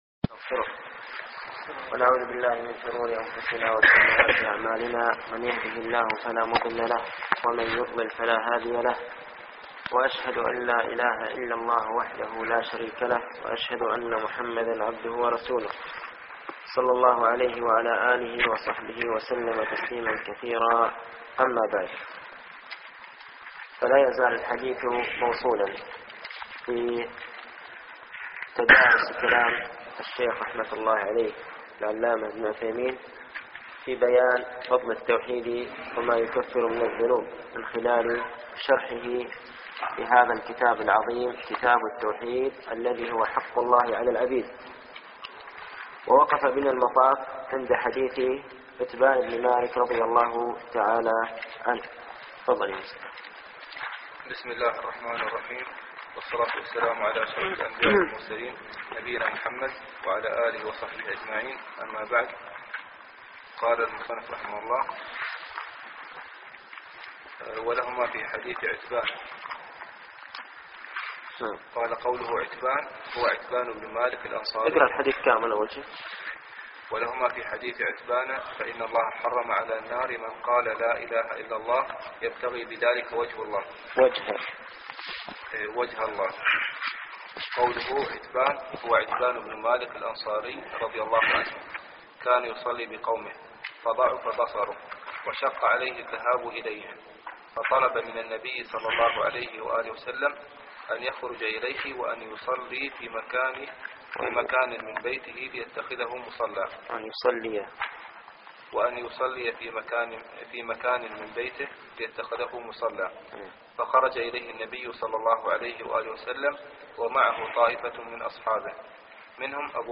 التعليق على القول المفيد على كتاب التوحيد - الدرس السابع